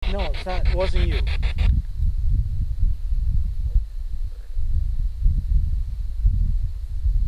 a loud voice is captured saying “Hama”. No idea what it means.